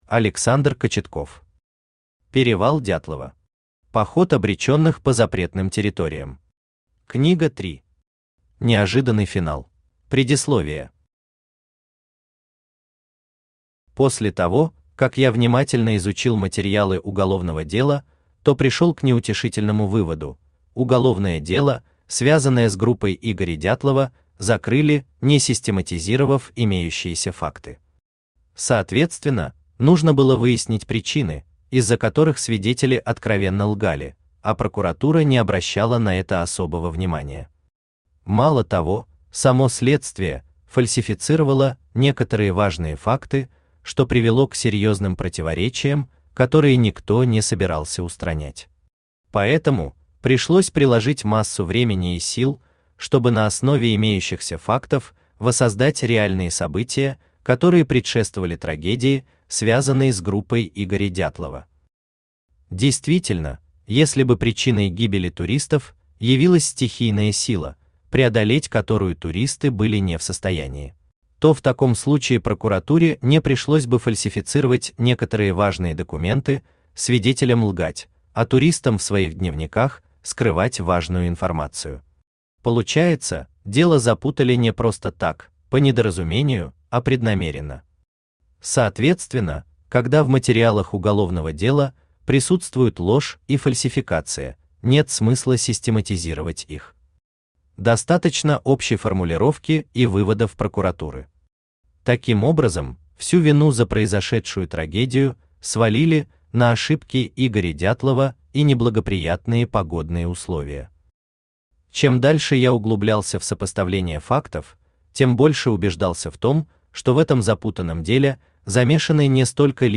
Аудиокнига Перевал Дятлова. Поход обреченных по запретным территориям. Книга 3. Неожиданный финал | Библиотека аудиокниг
Неожиданный финал Автор Александр Кочетков Читает аудиокнигу Авточтец ЛитРес.